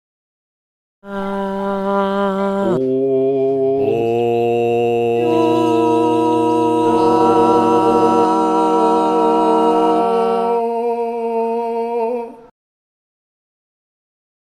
Zufällig getroffene Personen in der ganzen Schweiz gaben mir gesungene stehende Töne, mobil aufgenommen auf einen Audio-recorder. Mit diesen Stimmen bildete ich kurze mix-Chörli.